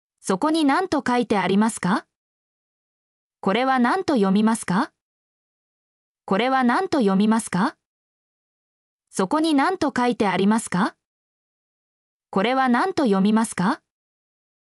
mp3-output-ttsfreedotcom-6_rVAGA4Iq.mp3